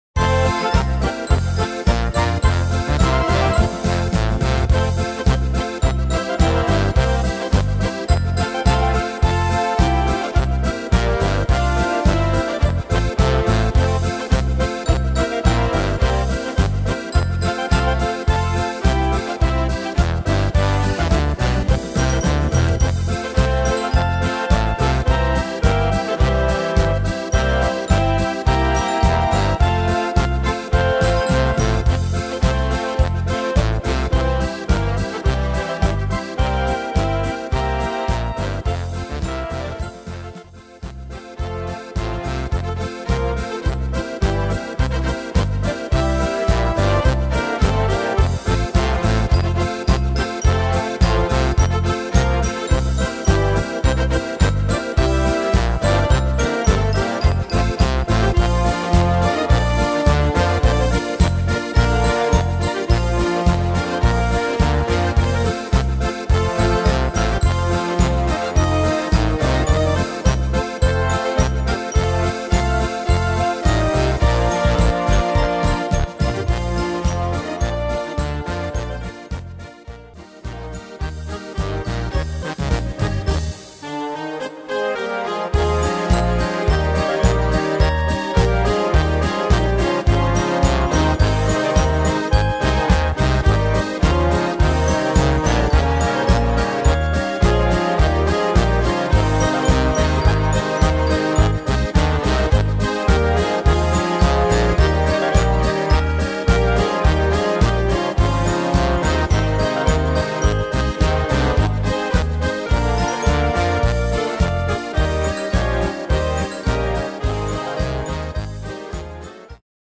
Tempo: 106 / Tonart: F-Dur